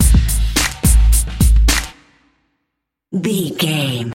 Aeolian/Minor
drum machine
synthesiser
drums
hip hop
Funk
neo soul
energetic
bouncy
funky